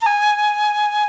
D3FLUTE83#04.wav